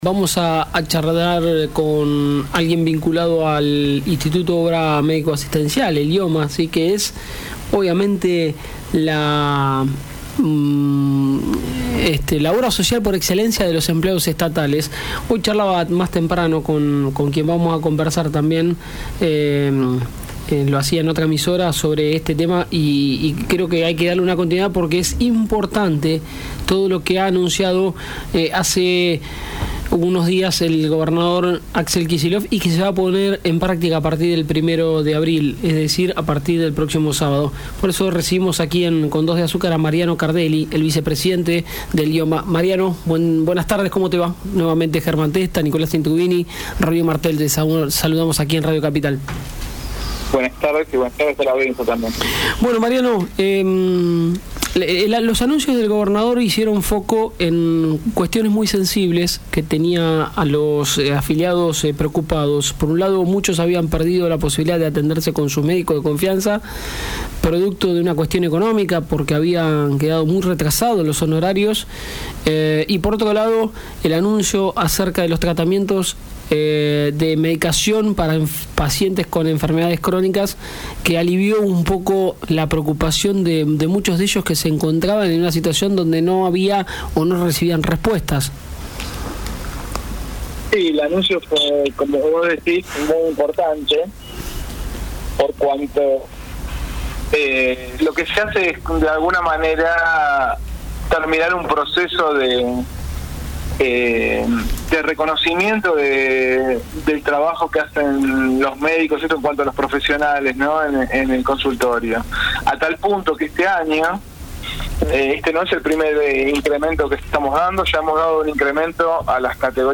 Mariano Cardelli, el vicepresidente de IOMA, dialogó con Radio Capital FM 91.3 en el Programa “Con 2 de azúcar”
Cardelli-en-Radio-Capital.mp3